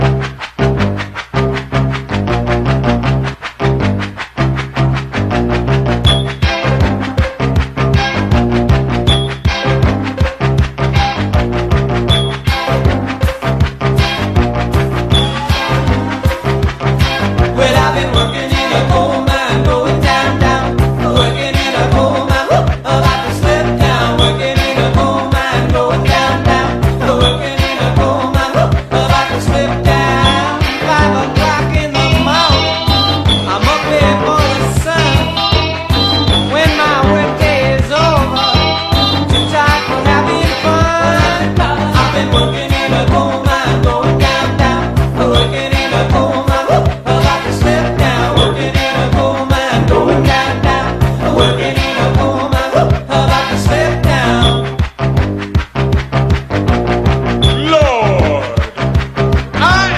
ROCK / 70'S / PSYCHEDELIC (UK)